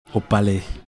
Au palais play all stop prononciation Au palais.